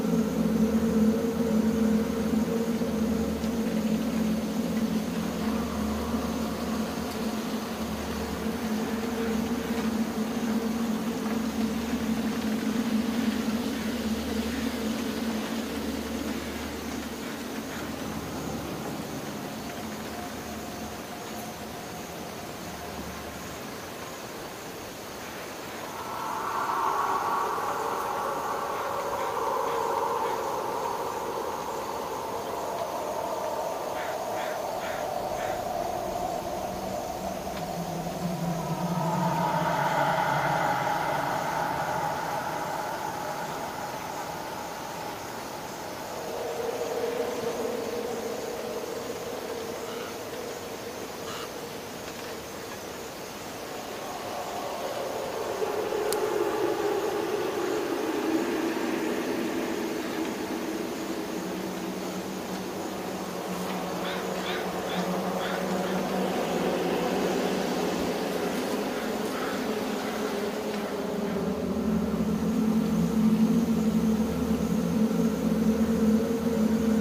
白噪声书店外.wav